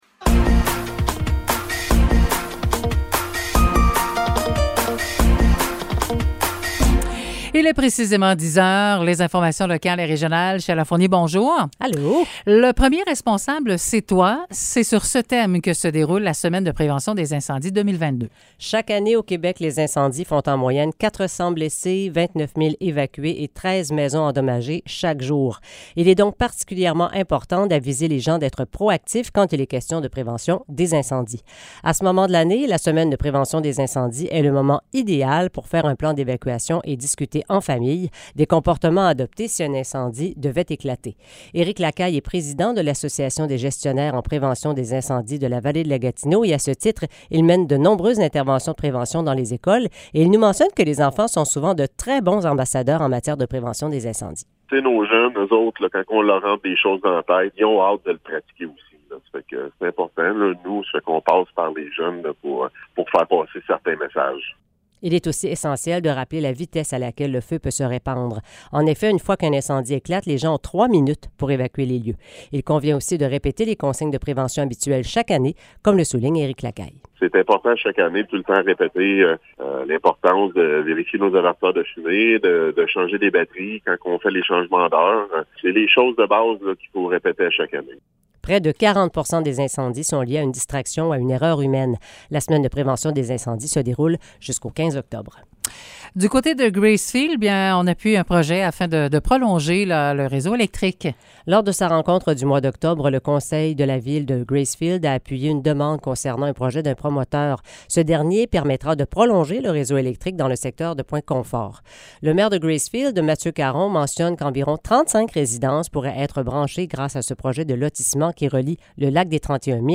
Nouvelles locales - 13 octobre 2022 - 10 h